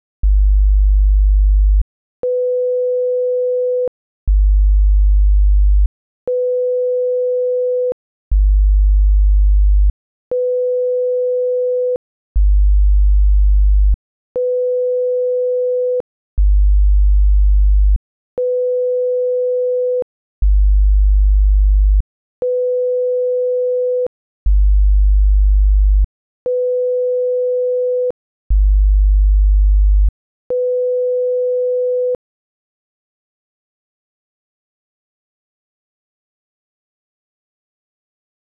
Both include two sine waves, one at 50 Hz and the other at 500 Hz, played at the same volume.
On the other track, the tones alternate in 2-second intervals.
alt_tones.wma